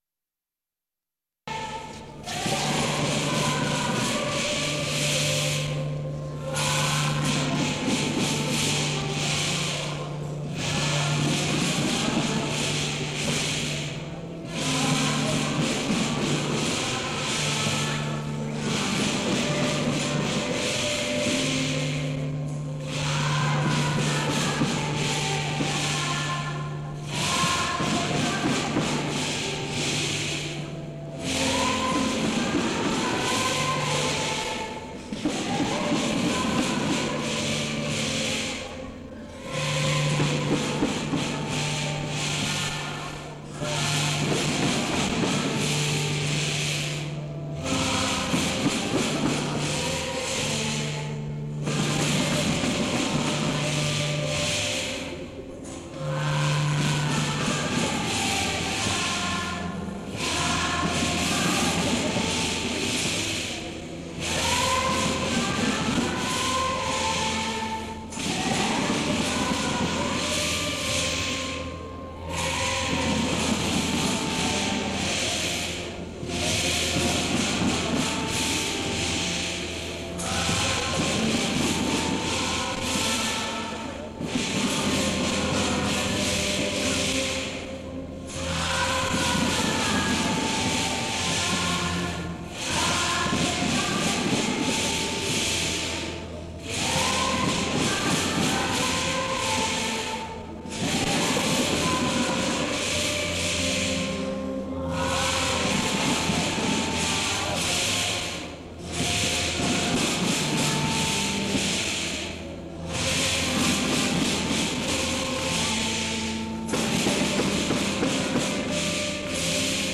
01 Posada en el santuario de La Candelaria
Paisaje Sonoro
Incluye cantos religiosos e interpretación colectiva de
Tlacotalpan, Veracruz, Mexico